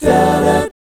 1-EMI7    -L.wav